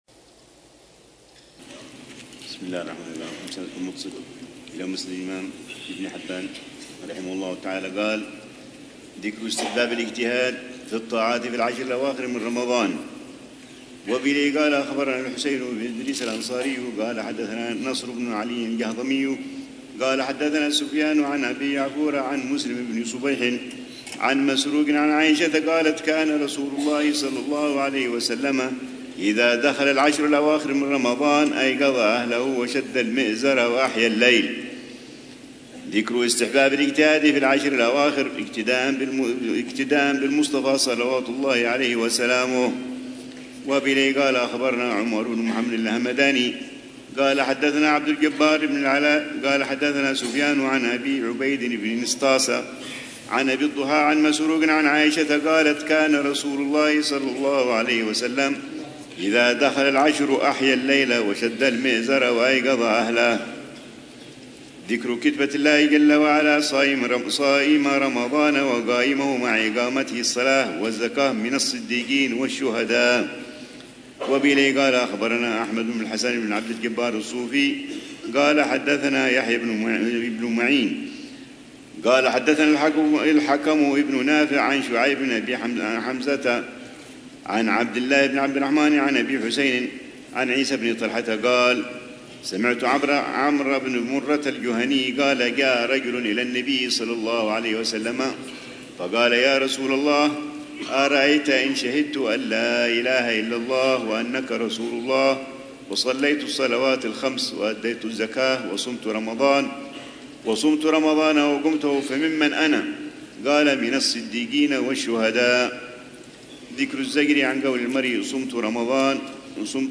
الروحة الرمضانية الثامنة بدار المصطفى لعام 1446هـ ، وتتضمن شرح الحبيب العلامة عمر بن محمد بن حفيظ لكتاب الصيام من صحيح ابن حبان، وكتاب الصيام